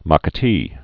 (mäkə-tē)